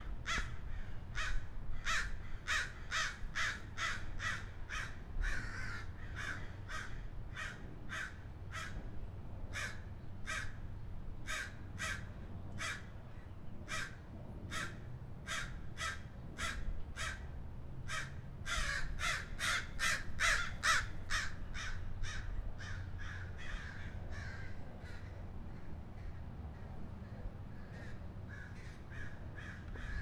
animals / crows